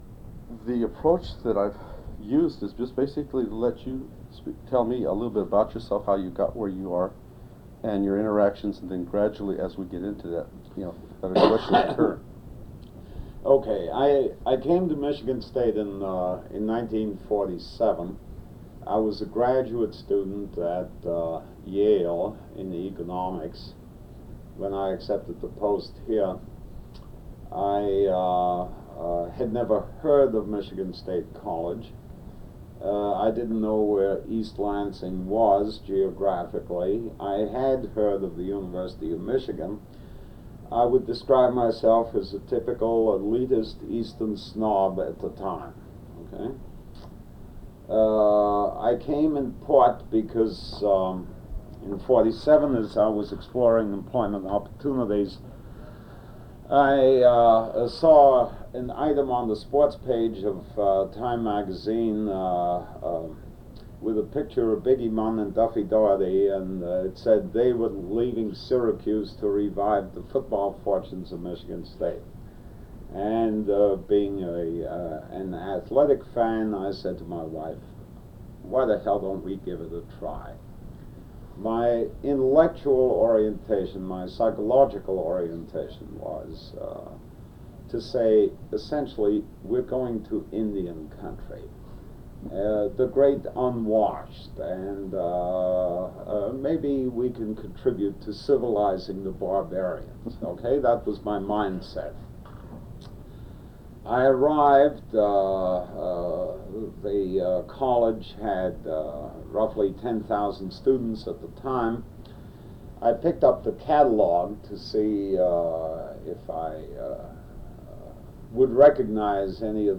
Interview with Walter Adams, 1990
Date: March 3, 1990 Format: Audio/mp3 Original Format: Audio cassette tape Resource Identifier: A008651 Collection Number: UA 10.3.156 Language: English Rights Management: Educational use only, no other permissions given.